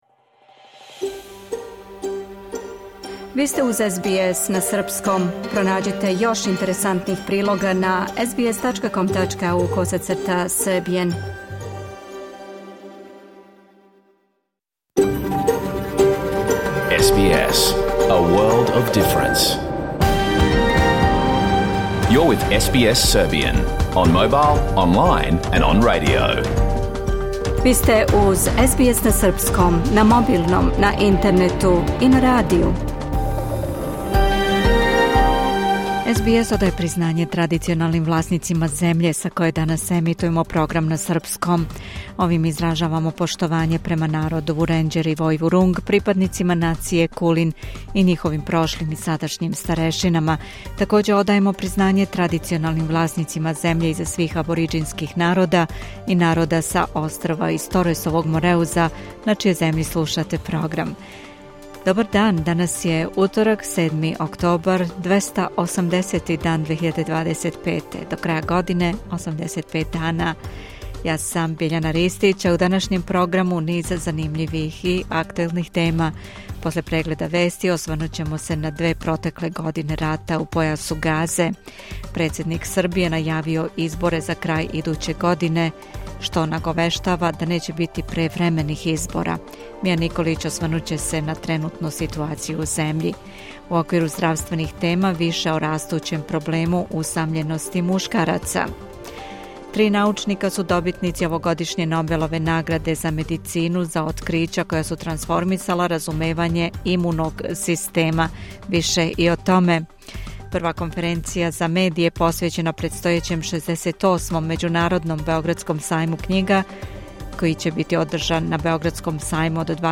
Програм емитован уживо 7. октобра 2025. године